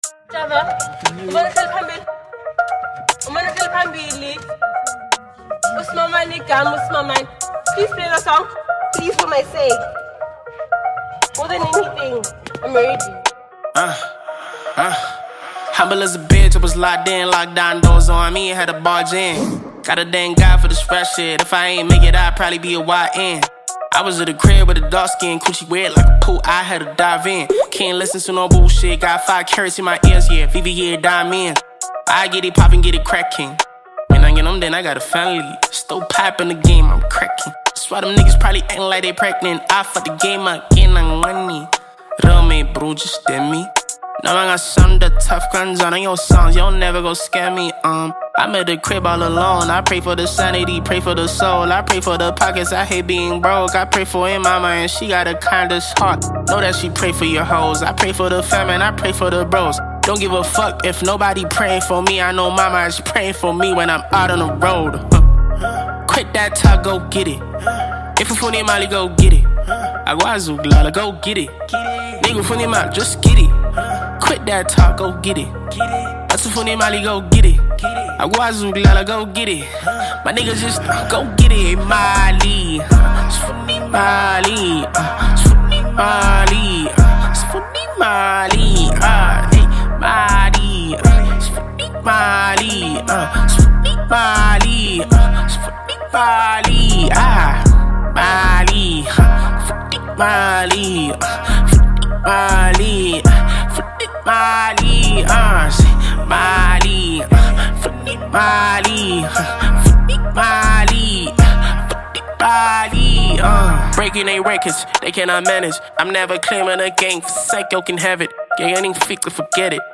Home » DJ Mix » Amapiano